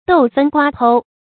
豆分瓜剖 注音： ㄉㄡˋ ㄈㄣ ㄍㄨㄚ ㄆㄡ 讀音讀法： 意思解釋： 見「豆剖瓜分」。